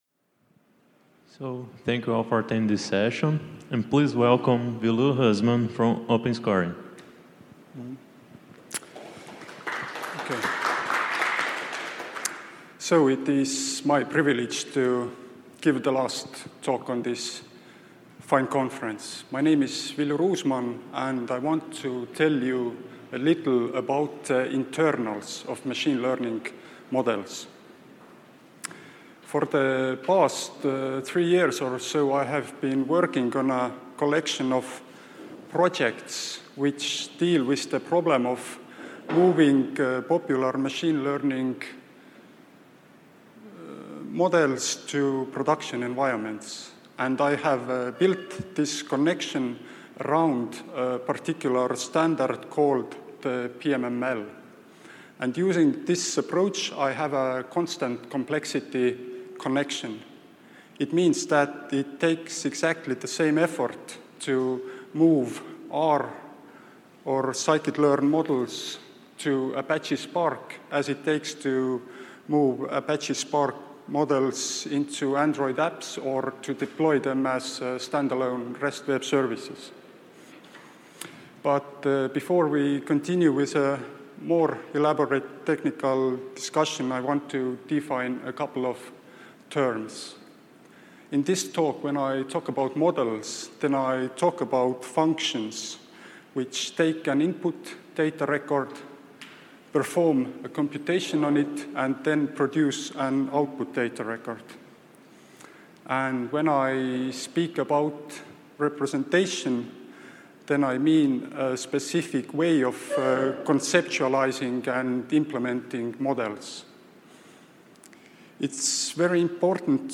This talk inquires into popular R, Scikit-Learn and Apache Spark model types, and connects them at a standardized PMML representation level. PMML adds value to all stages of the workflow, starting from model interpretation, reorganization and persistence, and ending with fully-automated model deployment to schema-full Big Data frameworks.